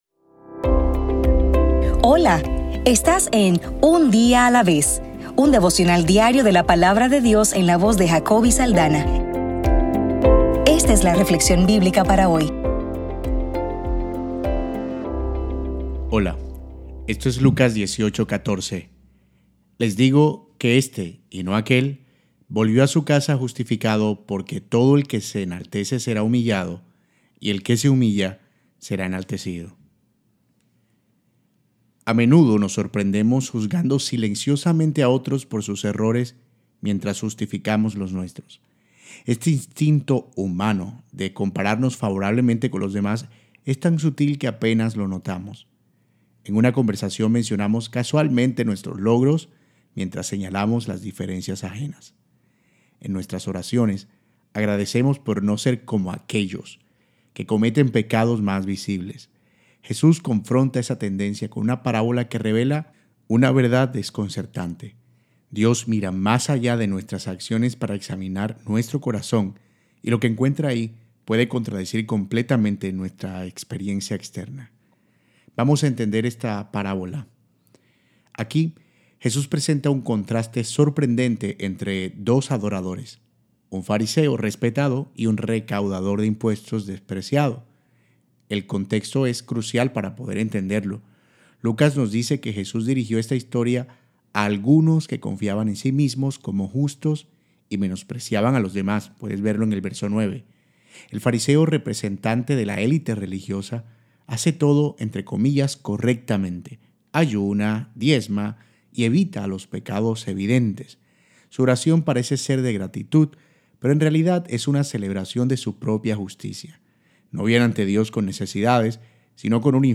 Devocional para el 4 de marzo